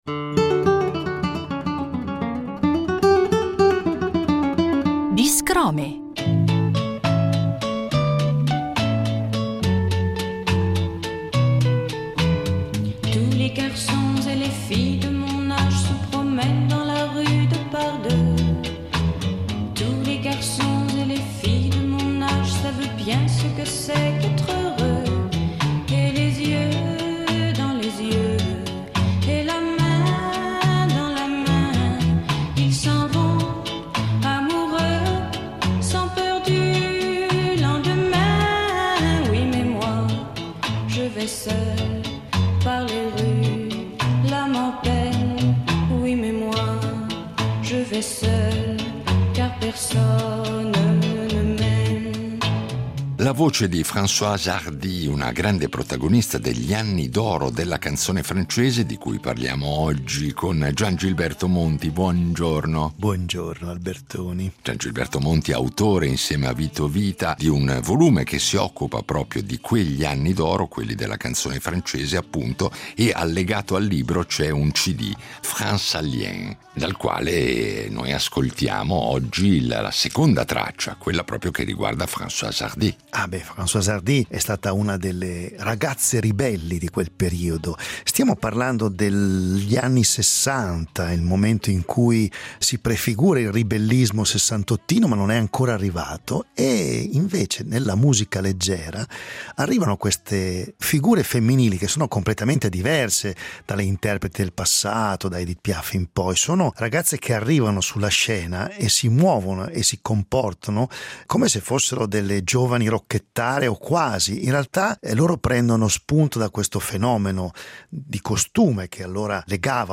registrato dal vivo con una band di stampo jazzistico